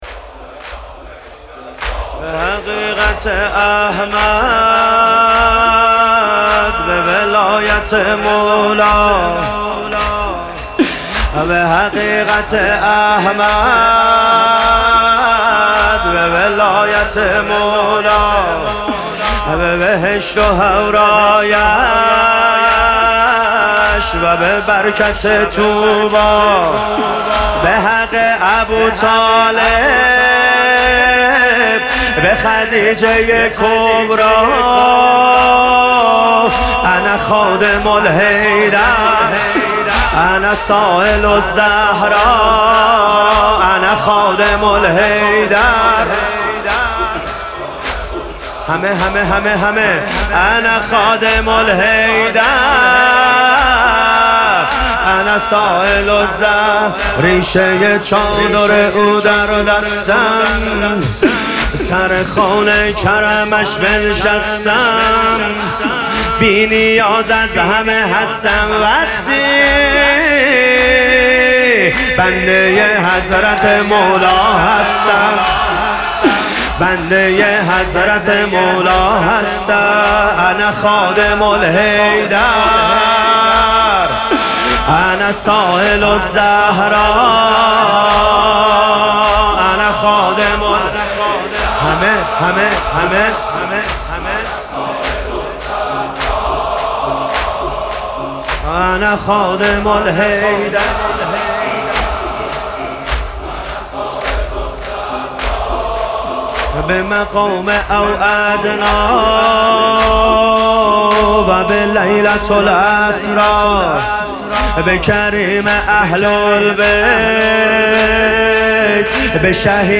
شب هفتم مراسم هشت شب عاشقی ماه رمضان
مداح